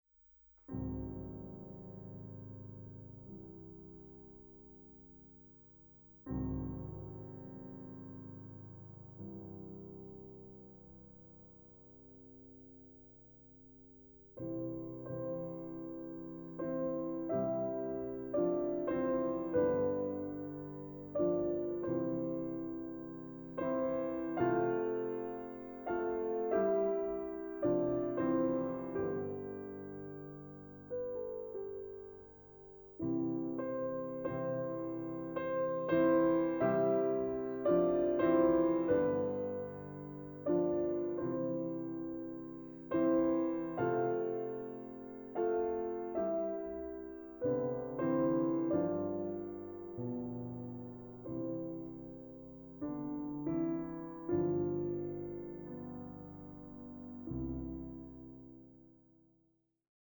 Klavierwerken aus sechs Jahrhunderten